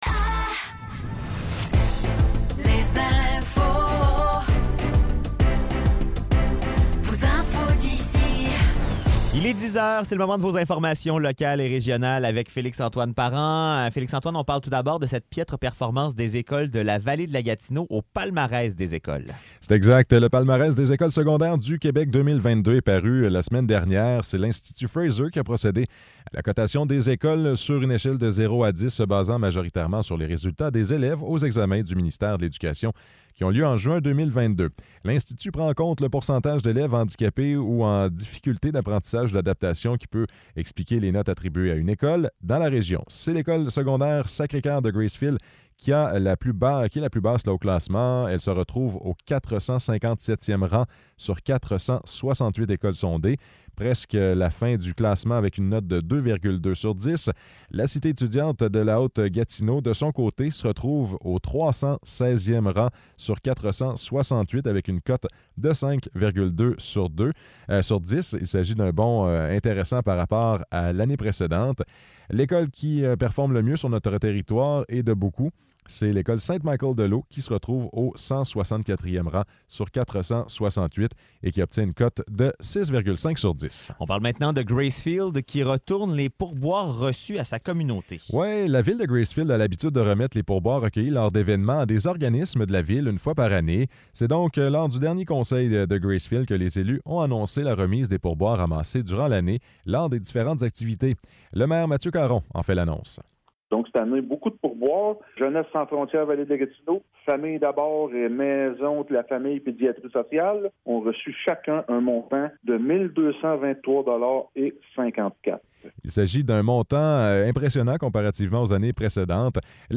Nouvelles locales - 28 novembre 2023 - 10 h